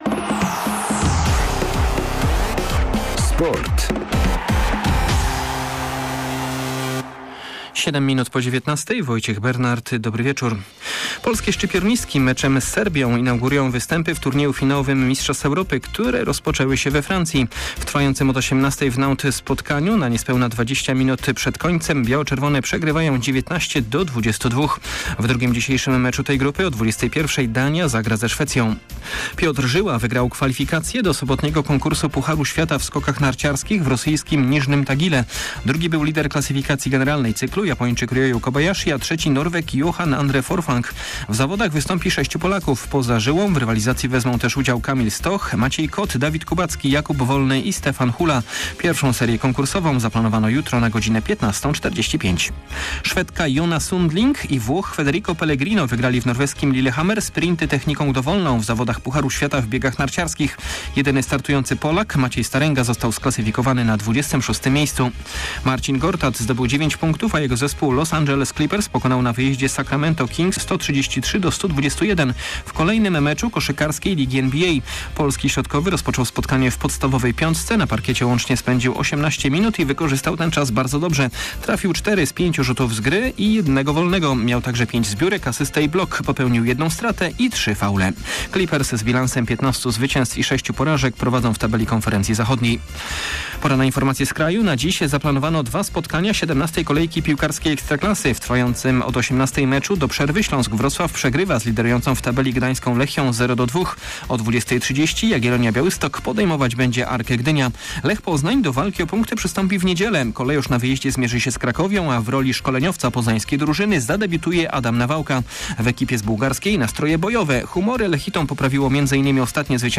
30.11. SERWIS SPORTOWY GODZ. 19:05